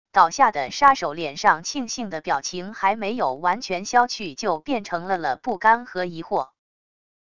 倒下的杀手脸上庆幸的表情还没有完全消去就变成了了不甘和疑惑wav音频生成系统WAV Audio Player